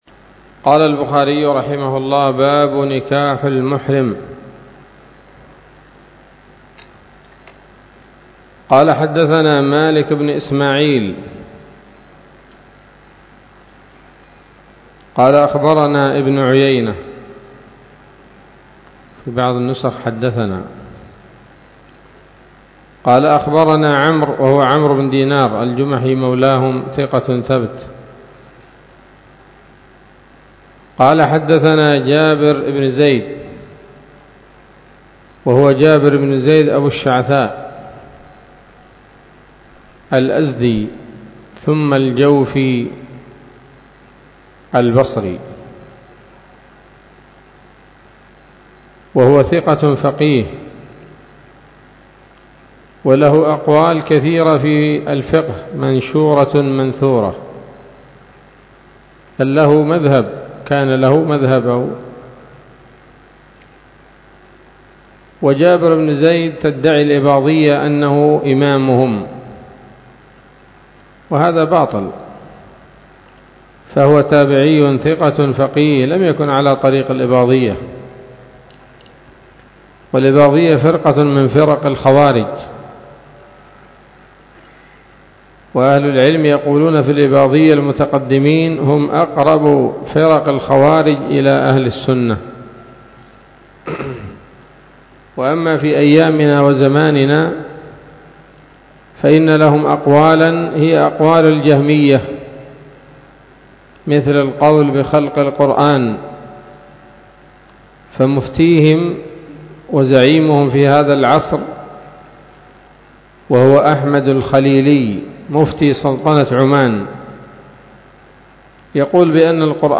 الدرس التاسع والعشرون من كتاب النكاح من صحيح الإمام البخاري